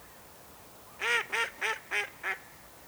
Anas platyrhynchos
Canto